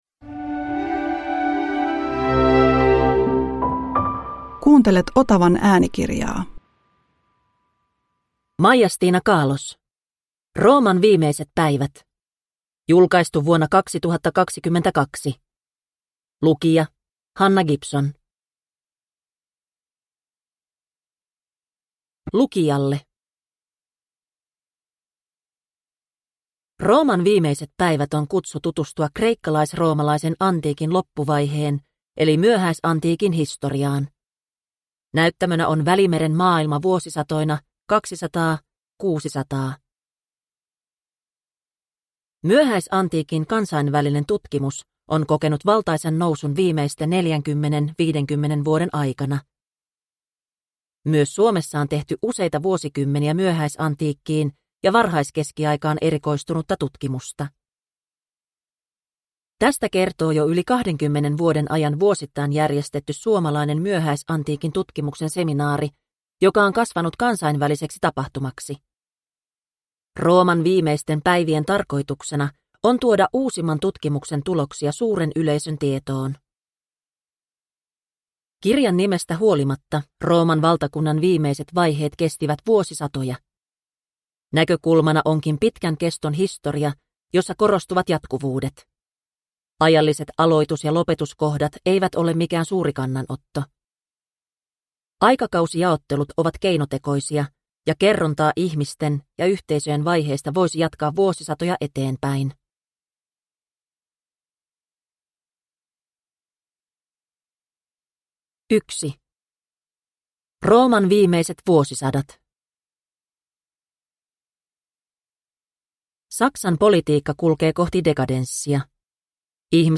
Rooman viimeiset päivät – Ljudbok – Laddas ner